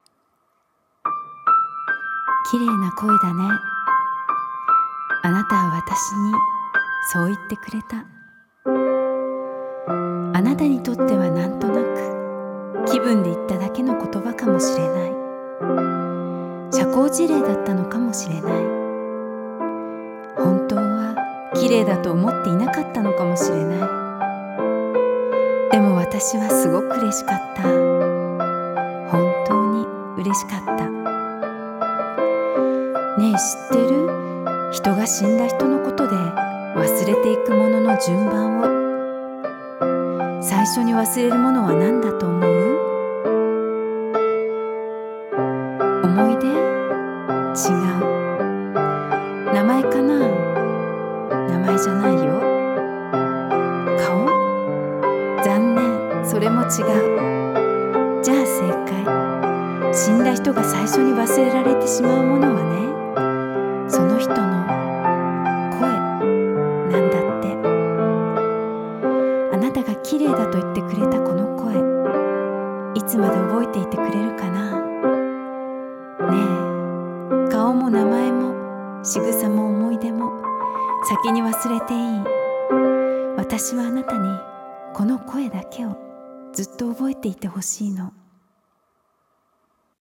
声劇・朗読『声』